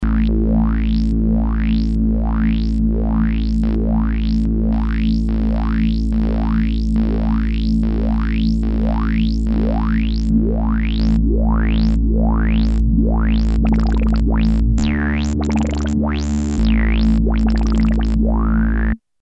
8 voices polyphonic synthesizer
rawlfo.mp3